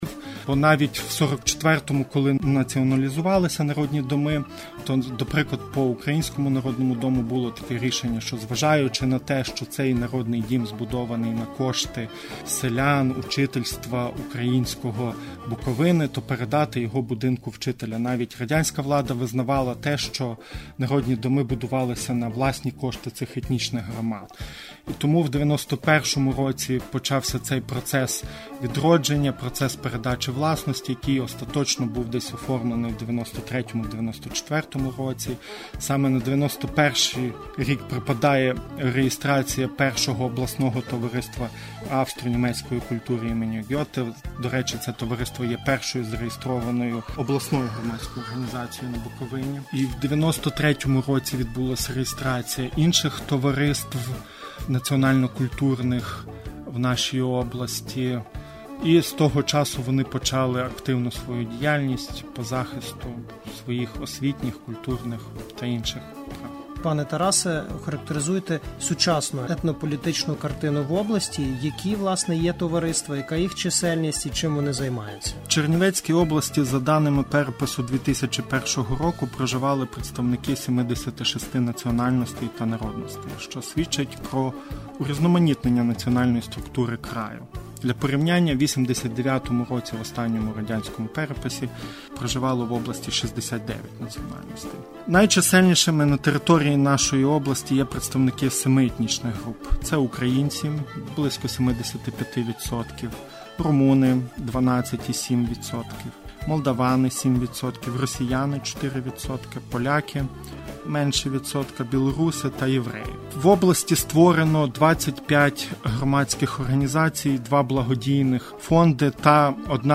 Гість у студії